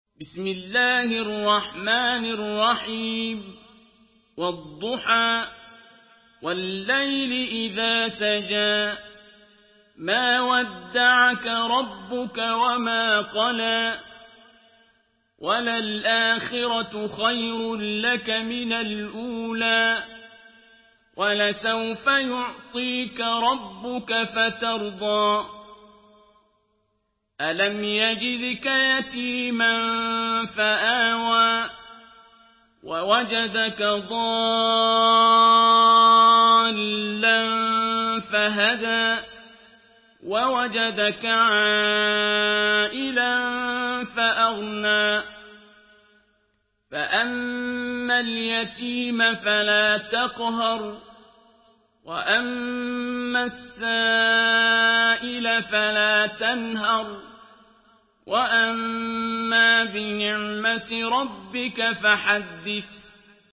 Surah আদ্ব-দ্বুহা MP3 in the Voice of Abdul Basit Abd Alsamad in Hafs Narration
Murattal Hafs An Asim